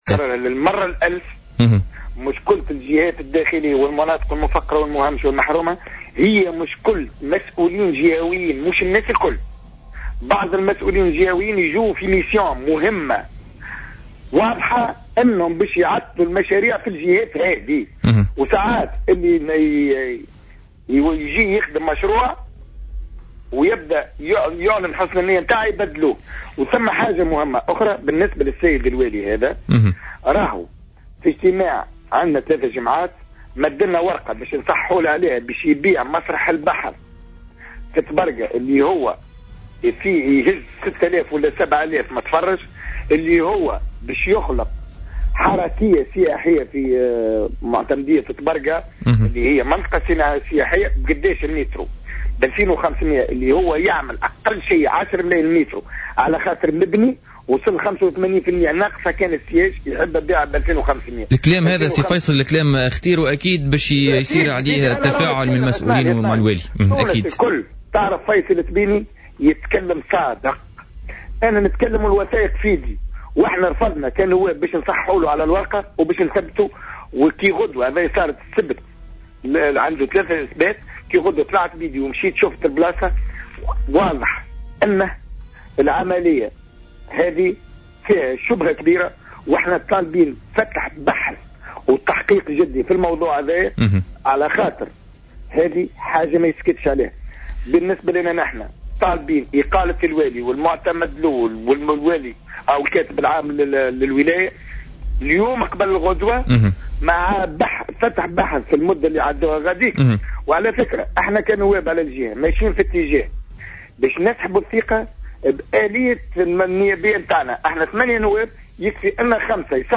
قال فيصل التبيني،نائب مجلس الشعب عن حزب صوت الفلاحين عن ولاية جندوبة في مداخلة له اليوم في برنامج "بوليتيكا" إن والي جندوبة طلب مؤخرا من نواب الجهة إمضاءاتهم من أجل التفويت في قطعة أرض مخصصة لبناء مشروع ثقافي في طبرقة "مسرح البحر" مقابل سعر زهيد يقدّر ب2500 د للمتر المربع،وفق تعبيره.